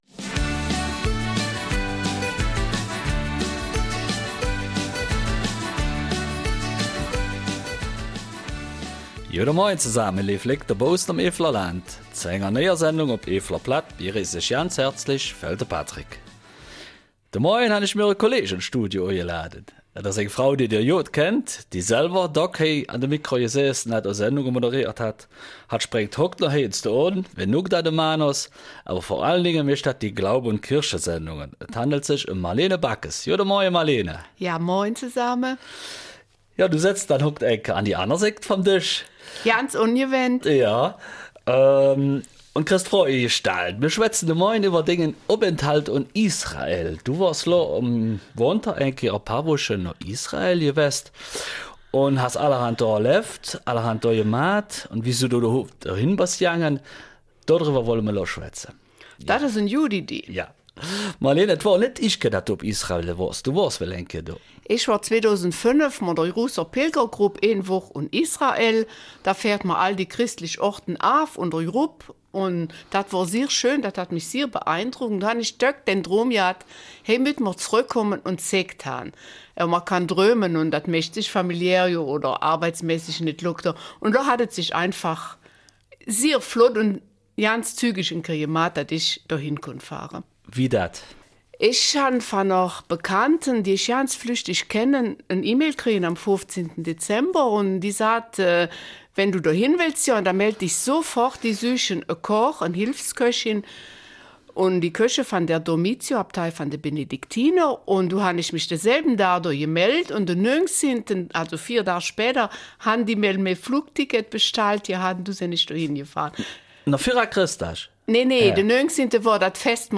Eifeler Mundartsendung
Eifeler Mundart